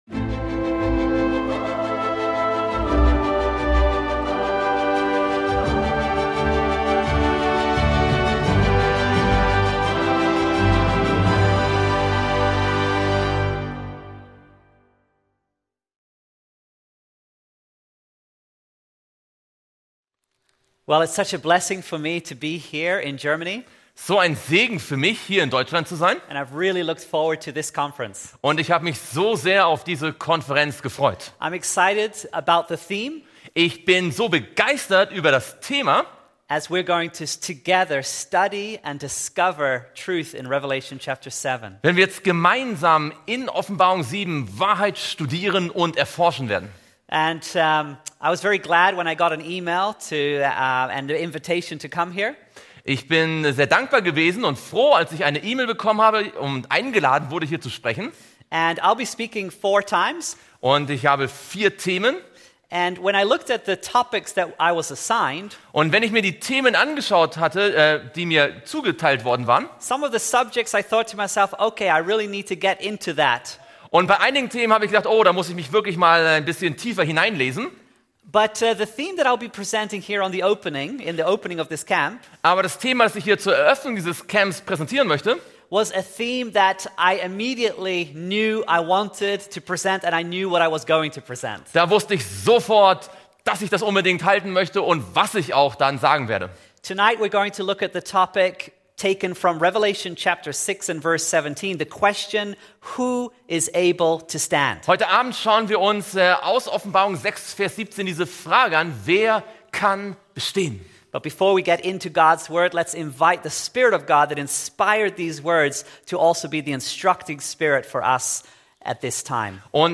In dieser packenden Konferenz wird die Offenbarung 7 im Kontext der biblischen Prophetie erforscht.